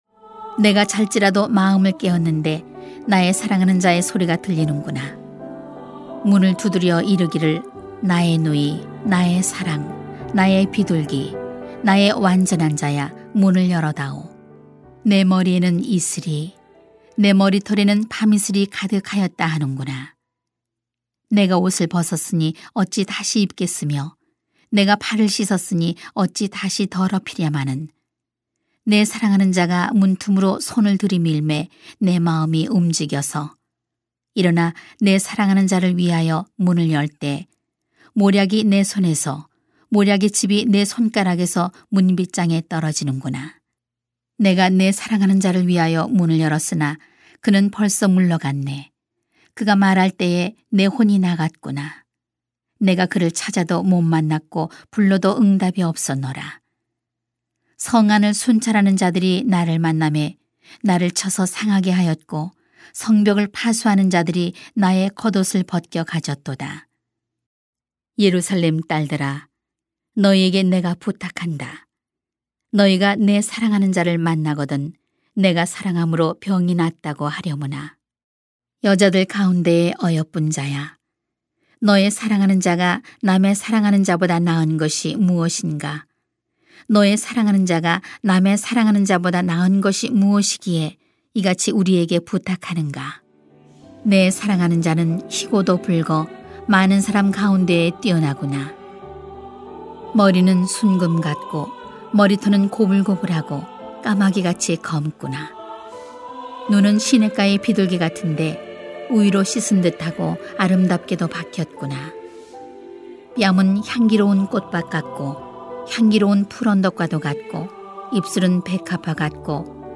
[아 5:2-6:3] 신속하게 응답하세요 > 주일 예배 | 전주제자교회